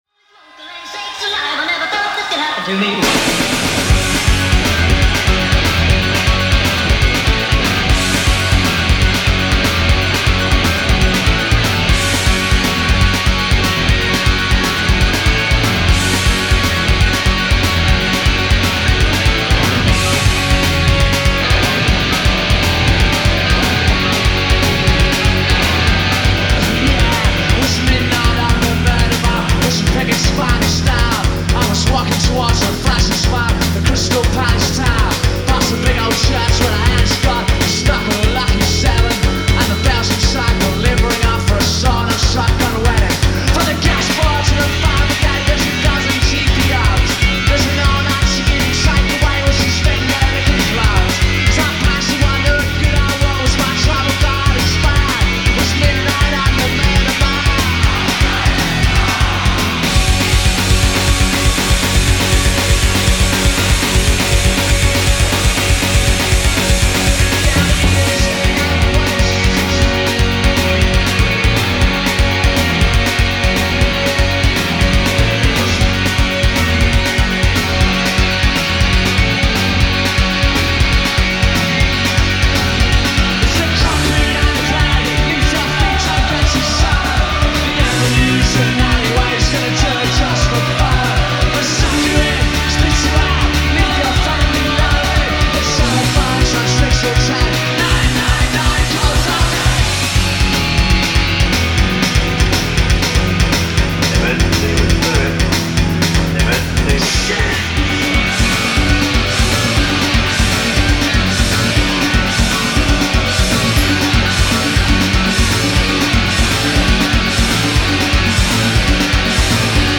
recorded live at KIlburn National, London